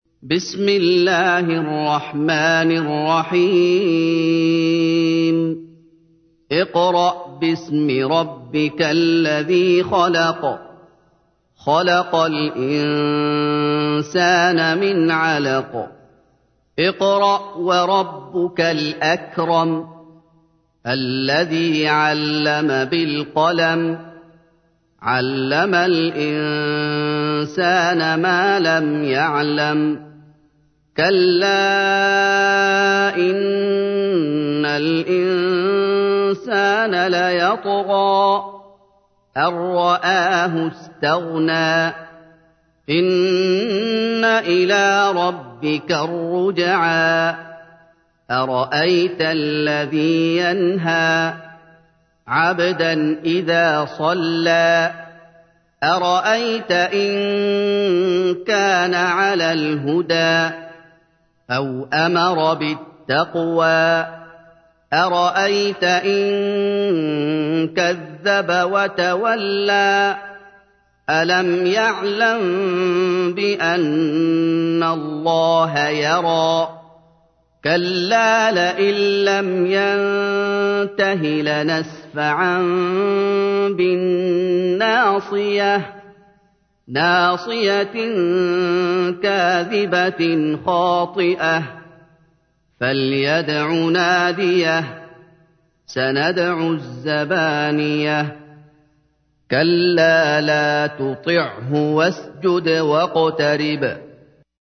تحميل : 96. سورة العلق / القارئ محمد أيوب / القرآن الكريم / موقع يا حسين